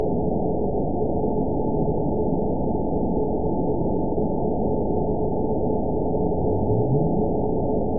event 920650 date 04/02/24 time 06:25:45 GMT (1 year, 1 month ago) score 9.62 location TSS-AB03 detected by nrw target species NRW annotations +NRW Spectrogram: Frequency (kHz) vs. Time (s) audio not available .wav